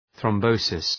{ɵrɒm’bəʋsıs}
thrombosis.mp3